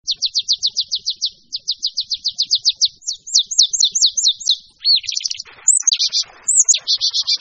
En cliquant ici vous entendrez le chant de la Mésange Nonette.